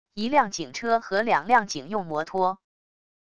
一辆警车和两辆警用摩托wav音频